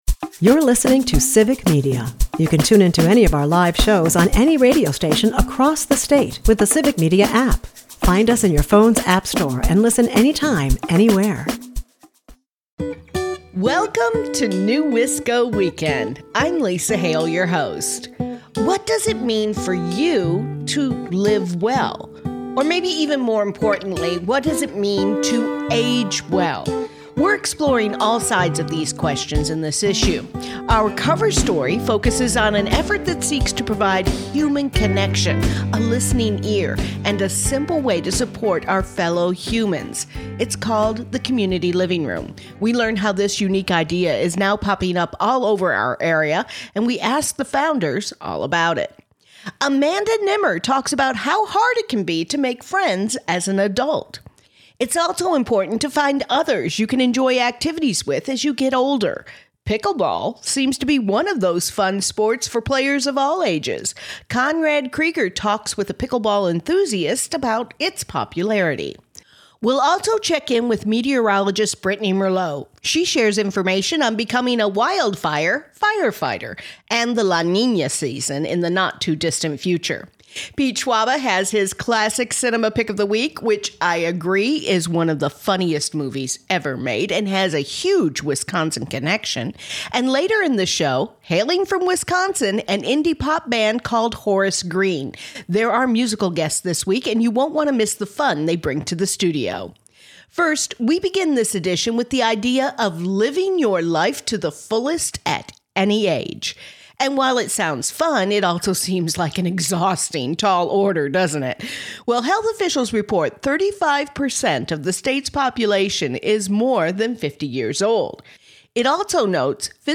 La Niña Watch has been issued NEWisco Weekend is a part of the Civic Media radio network and airs Saturdays at 8am & Sundays at 11am on 98.3 and 96.5 WISS and Sundays at 8 am on 97.9 WGBW .